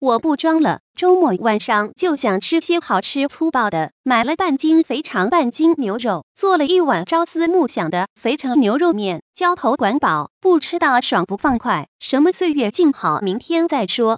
朗读女 一键绿化安装包 （免配置安装 自带1个本地女声发音人，解压即用！）
小燕语音库。